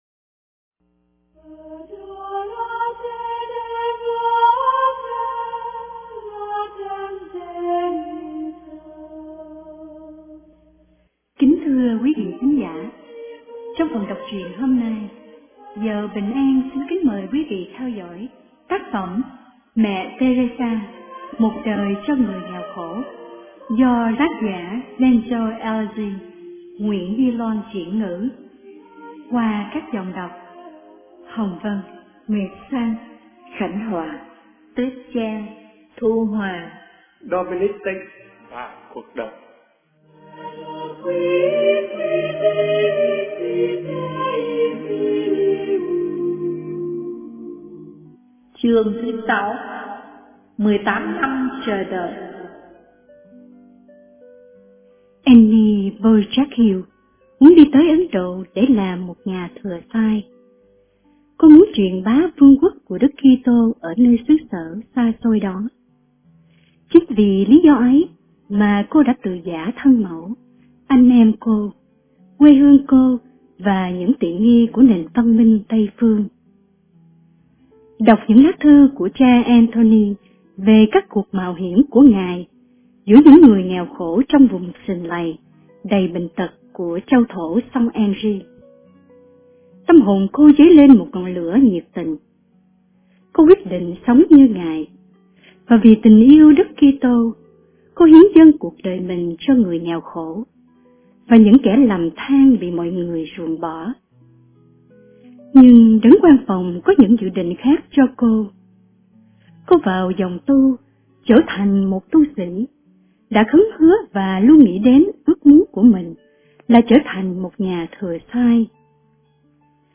Audio Book - Mẹ têrêxa Một Đời Cho Người Nghèo Khổ